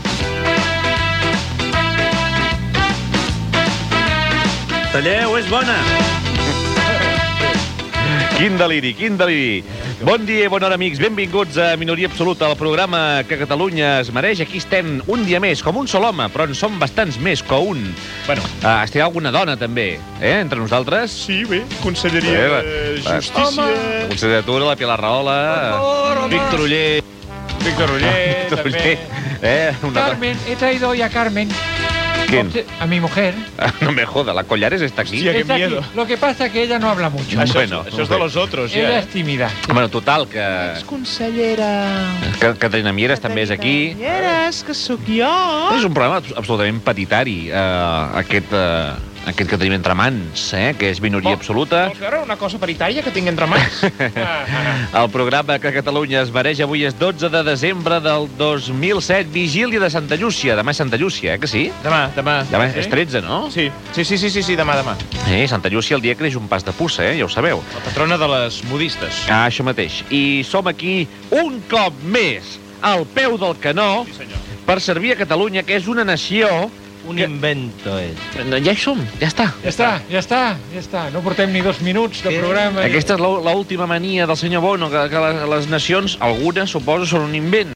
Salutació inicial del programa
Entreteniment